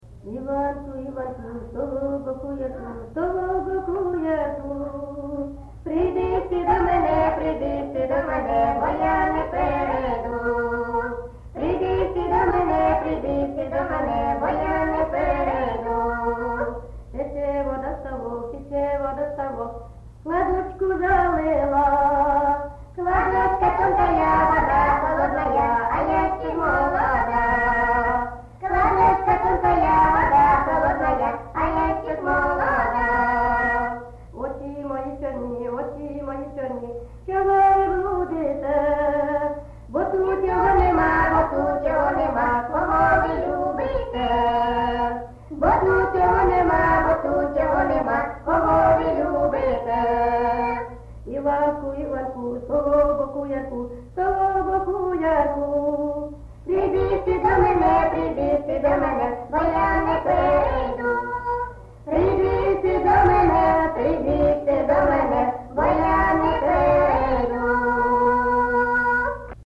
ЖанрПісні з особистого та родинного життя, Пісні літературного походження
Місце записус. Золотарівка, Сіверськодонецький район, Луганська обл., Україна, Слобожанщина